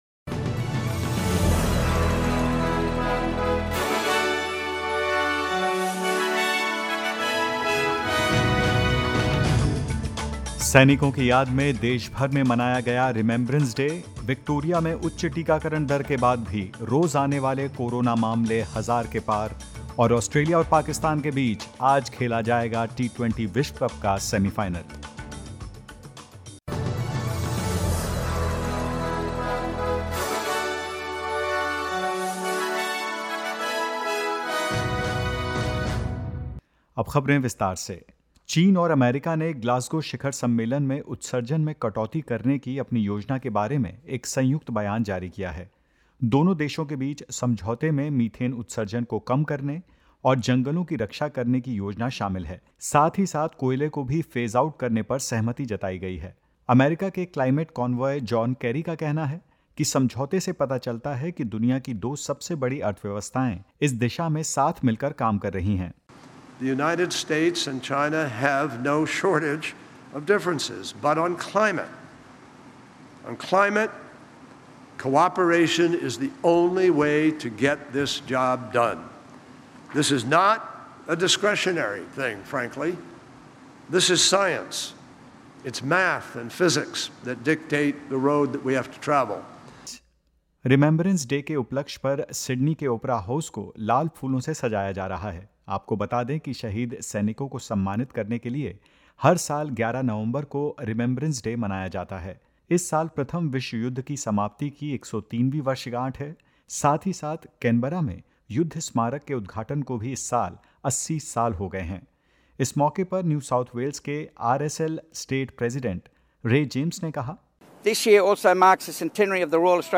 In this latest SBS Hindi news bulletin of Australia and India: Gold Coast on high alert after a second unlinked COVID-19 case; Victoria registers 1313 new COVID-19 cases and more.